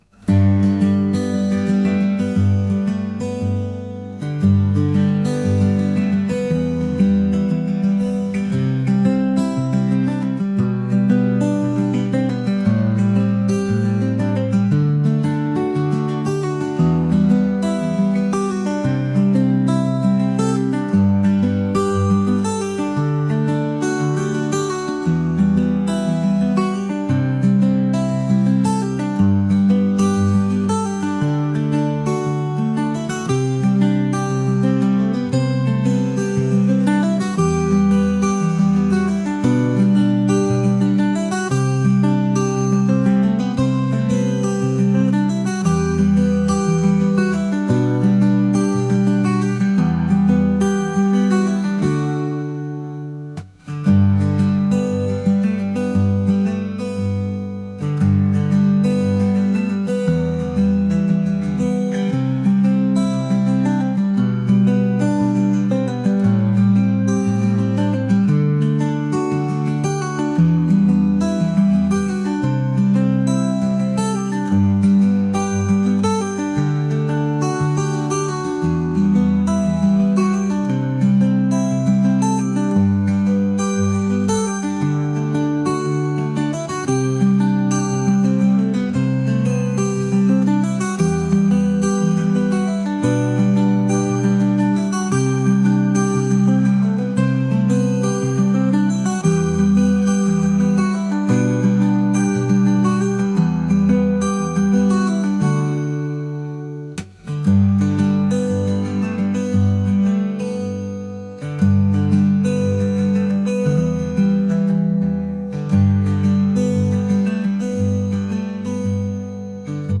acoustic | folk | singer/songwriter